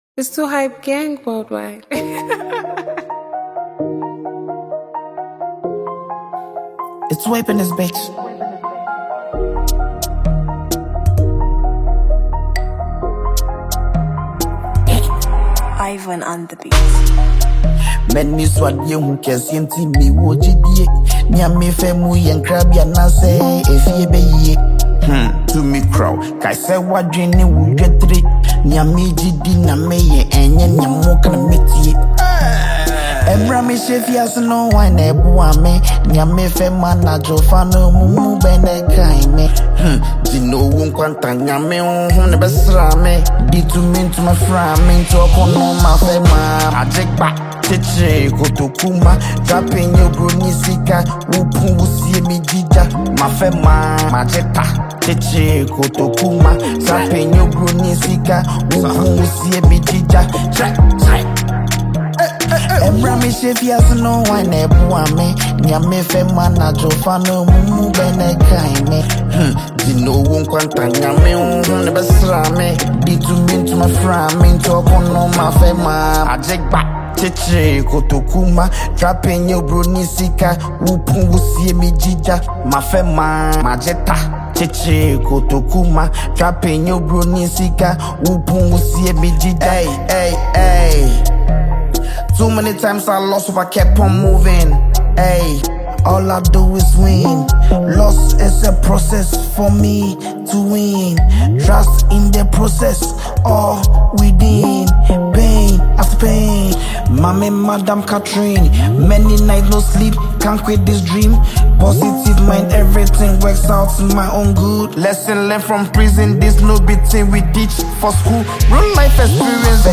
is a street anthem packed with confidence and energy
Genre: Drill / Hip-Hop